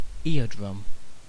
Ääntäminen
Ääntäminen UK UK : IPA : /ˈɪəˌdɹʌm/